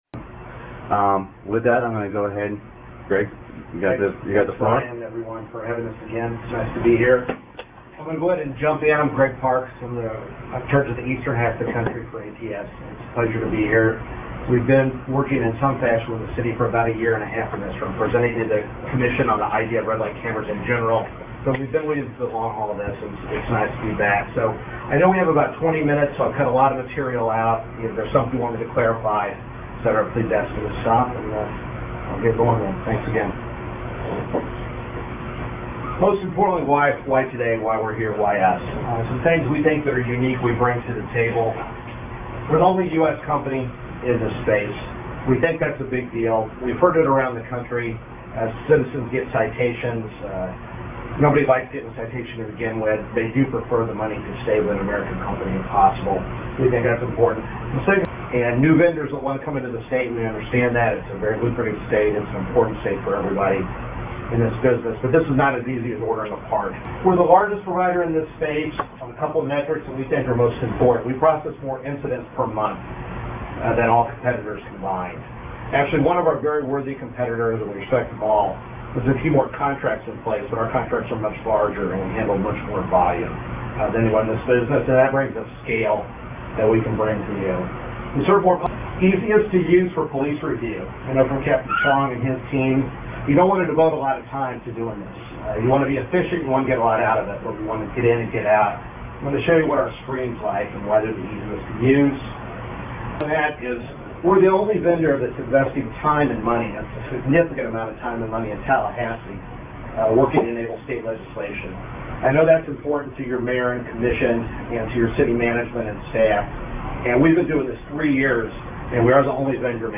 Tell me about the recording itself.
The winning vendor, ATS, made an hour-long presentation at an August 7 meeting with Homestead officials.